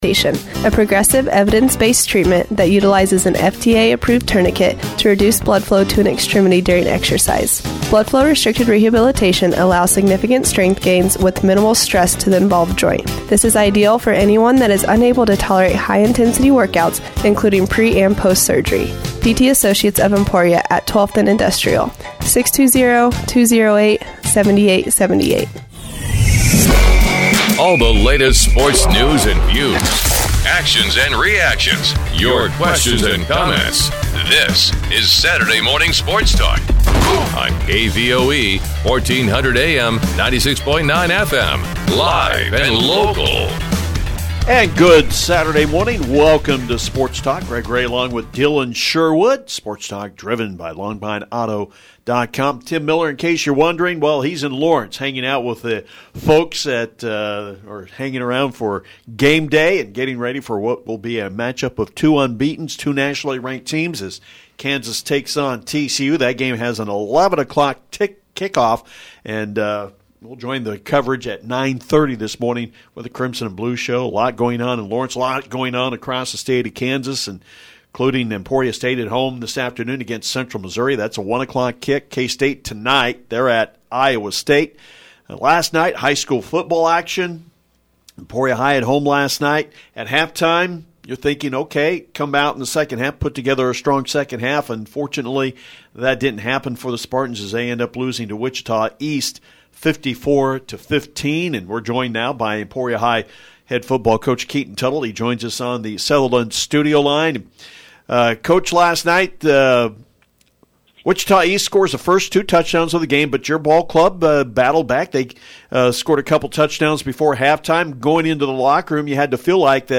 Mitch Holthus – Voice of the Kansas City Chiefs
sports-talk-10-8.mp3